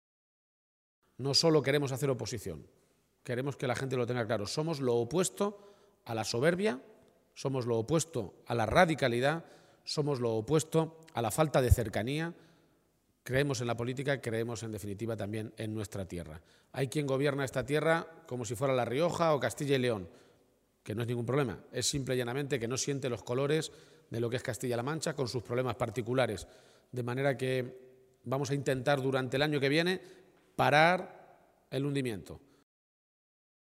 El secretario regional del PSOE de Castilla-La Mancha, Emiliano García-Page, ha participado esta mañana en el comité provincial del PSOE de Guadalajara en la localidad de Alovera.
Cortes de audio de la rueda de prensa